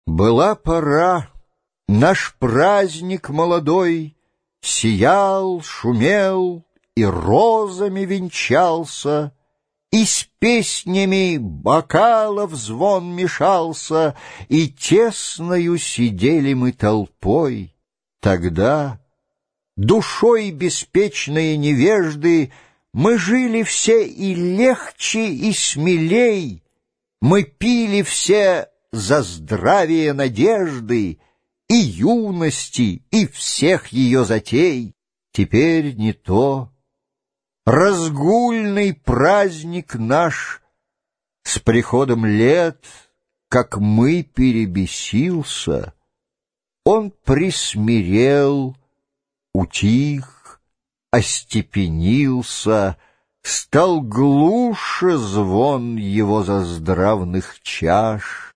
Аудиокнига Евгений Онегин. Лирика | Библиотека аудиокниг